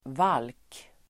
Uttal: [val:k]